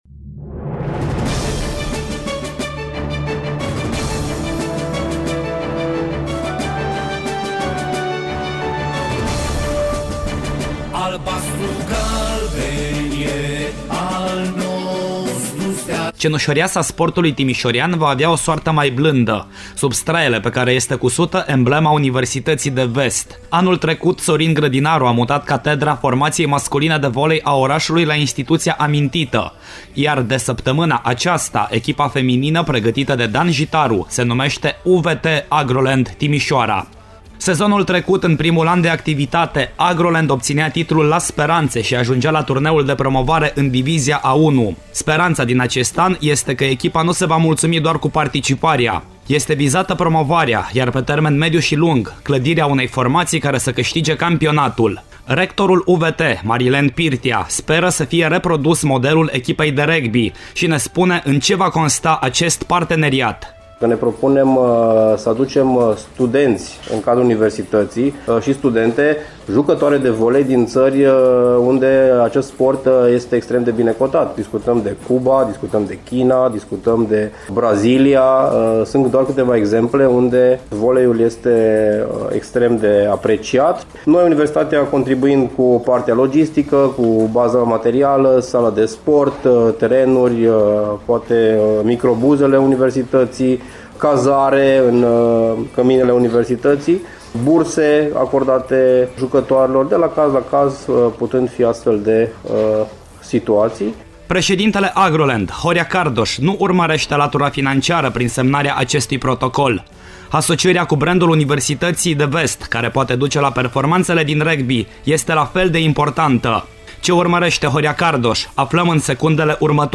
Reportaj audio / UVT susţine dezvoltarea voleiului timişorean